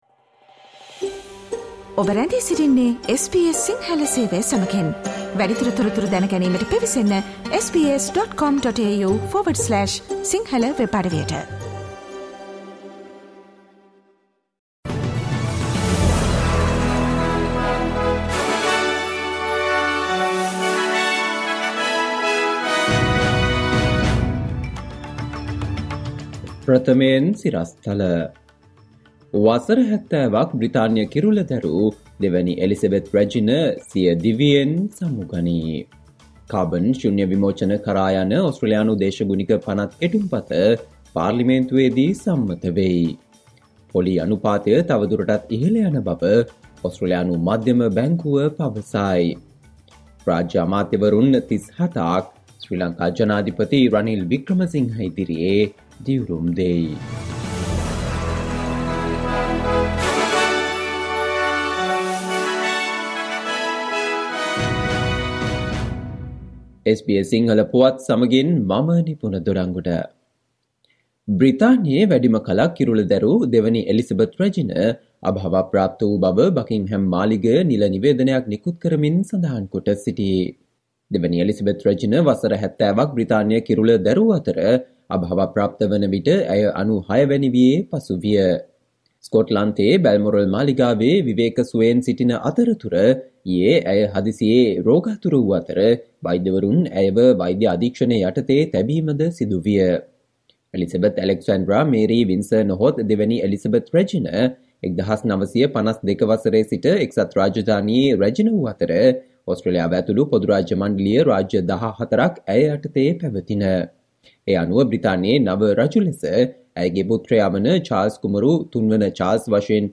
Listen to the SBS Sinhala Radio news bulletin on Friday 09 September 2022